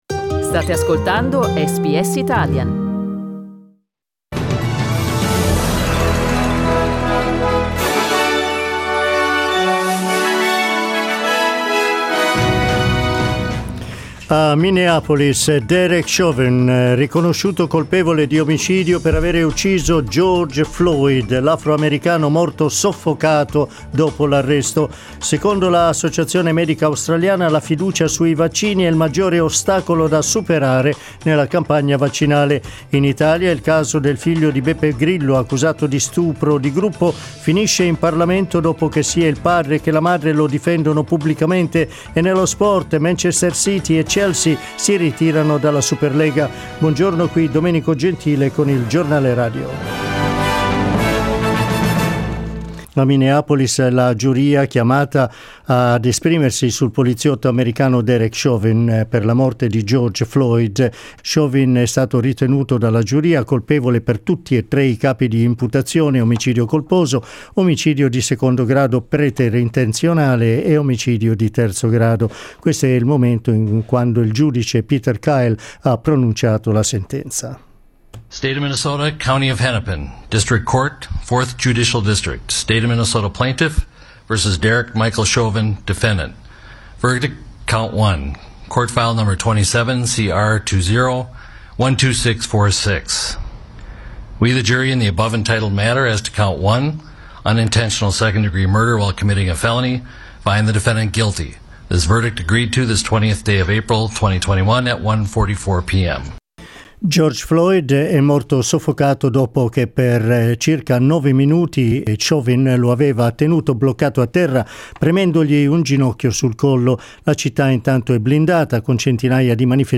Il notiziario in italiano di SBS Italian.